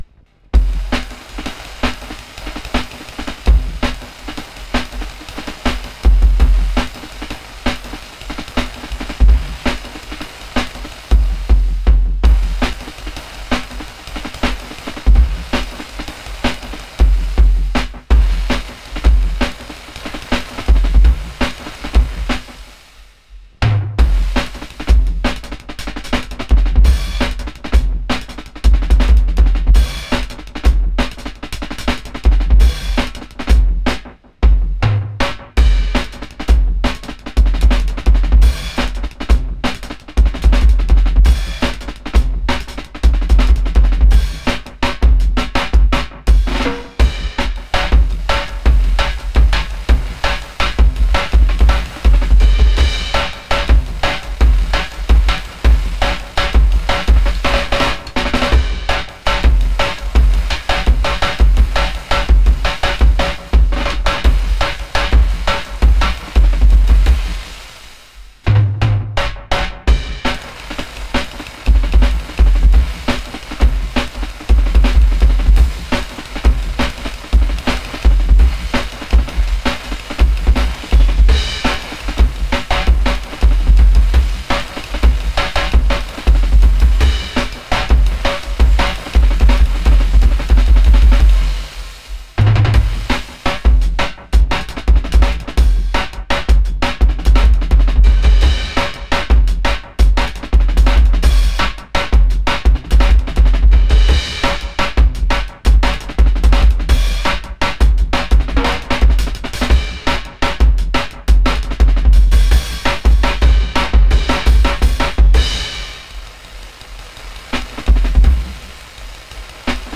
Index of /4 DRUM N BASS:JUNGLE BEATS/BEATS OF THE JUNGLE THAT ARE ANTIFUNGAL!!